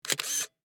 shutter.mp3